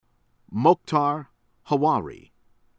HANOUNE, LOUISA loo-EE-sah   hah-NOON